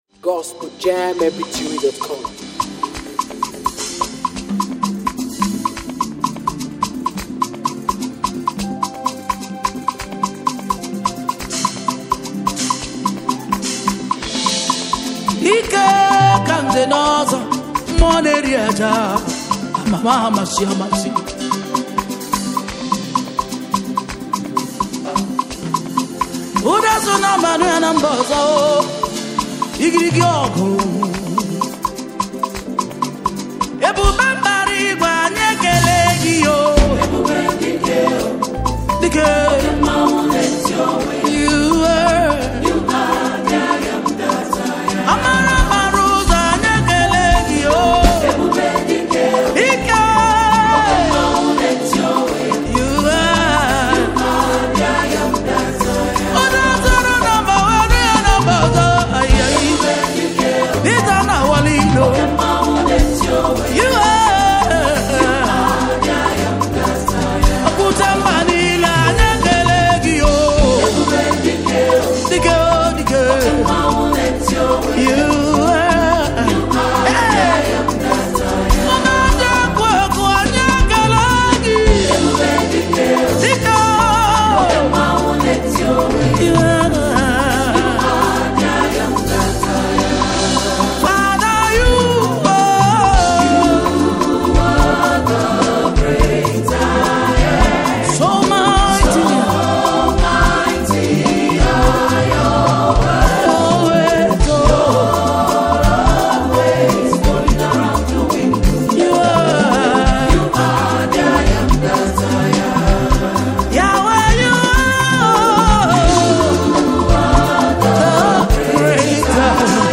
African praisemusic
gospel worship song
passionate vocals and strong spiritual delivery